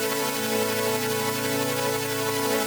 SaS_MovingPad01_90-A.wav